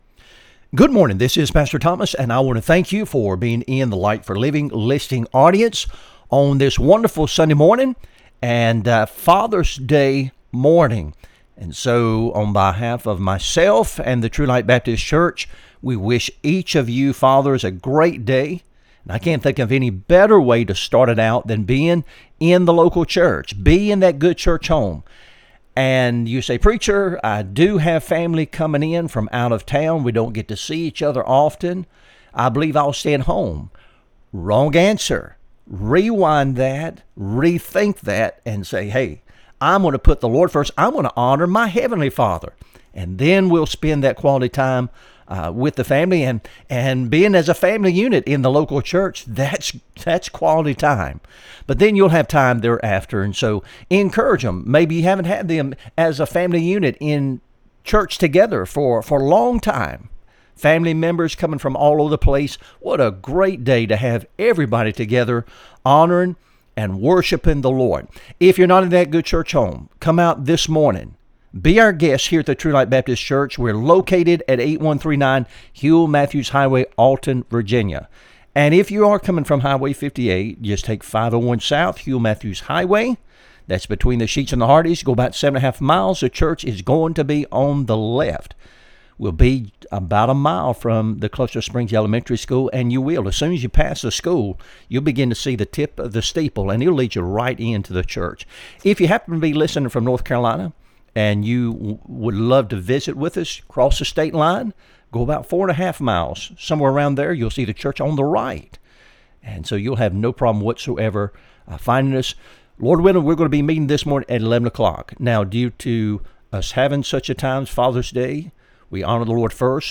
Sermons | True Light Baptist Church of Alton, Virginia
Light for Living Radio Broadcast